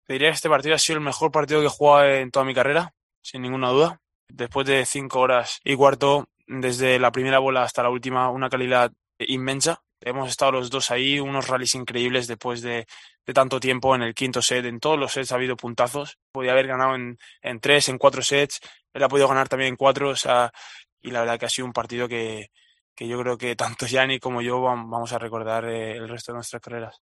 "Me siento muy, muy contento de haber sacado adelante un partido tan complicado contra Jannik. El nivel que hemos mostrado ha sido espectacular. Los dos nos hemos llevado al límite. Ahora toca disfrutar de este momento", dijo Alcaraz en español en la rueda de prensa posterior al partido de la pista Arthur Ashe.